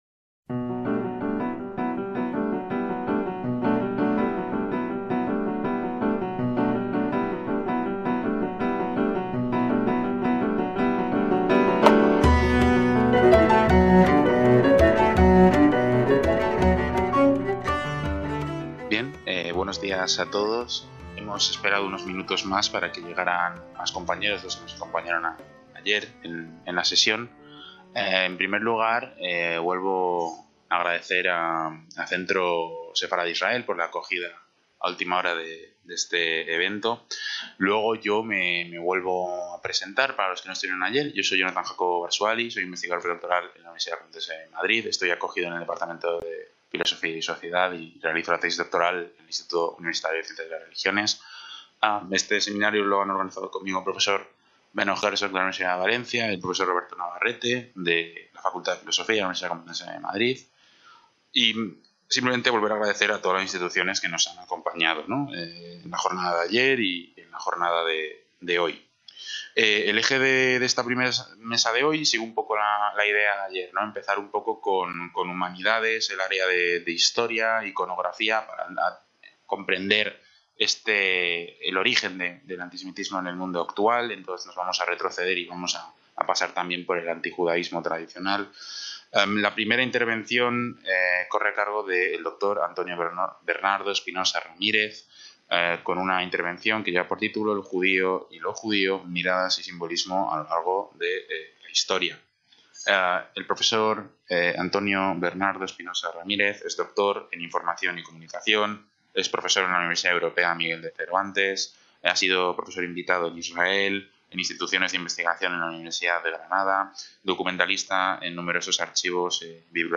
VII SEMINARIO INTERNACIONAL CONTRA EL ANTISEMITISMO